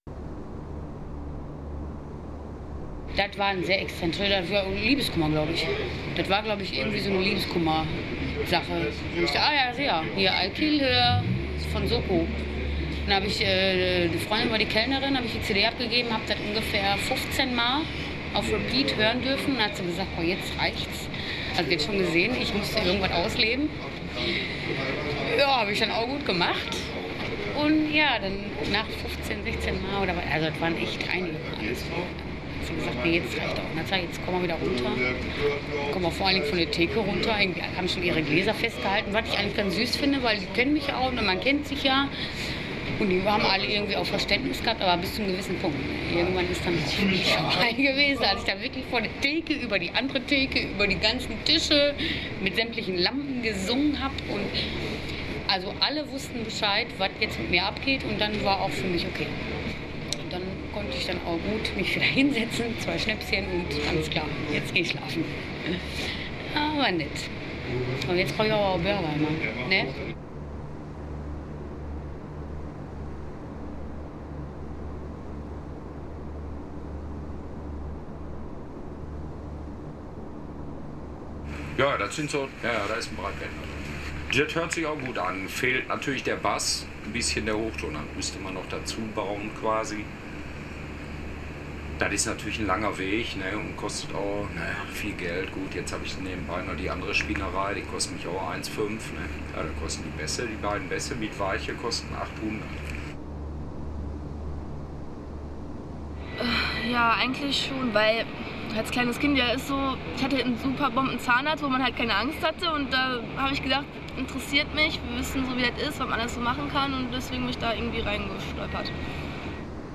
Alltagsstimmen aus der Metropole Ruhr
Everyday voices from Ruhr Metropolis
Binaurale Klangdateien: Bitte mit Kopfhörern hören / Binaural tracks: Please listen with headphones